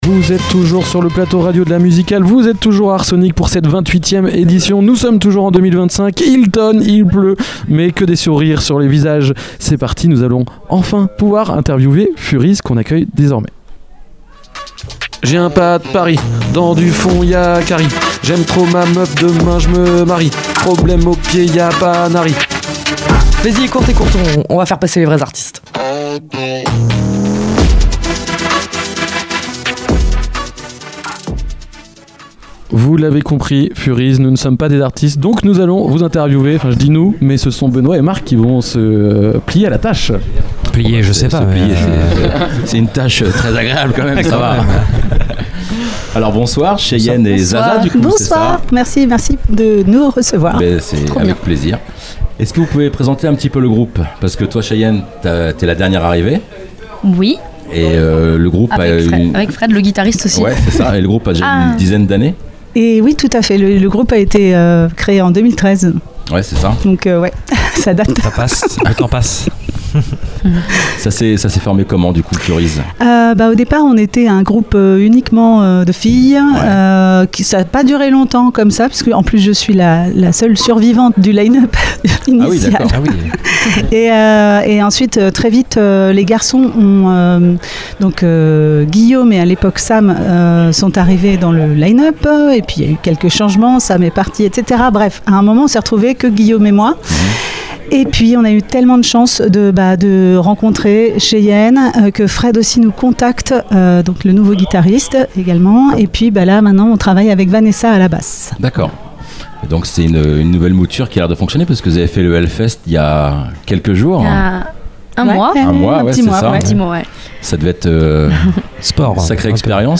Dans cette interview réalisée dans le cadre de l’émission spéciale enregistrée au festival Art Sonic à Briouze, les radios de l’Amusicale — Ouest Track, Station B, PULSE, Kollectiv’, 666, Radio Sud Manche, Radio Coup de Foudre, Radar, Phénix, Radio Campus Rouen et TST Radio — sont parties à la rencontre des artistes qui font vibrer le festival.
Une interview qui capture toute la puissance et la sincérité d’un groupe porté par la passion du live, au cœur de l’ambiance unique d’Art Sonic, aux côtés des nombreux acteurs qui participent à faire vivre ce festival incontournable.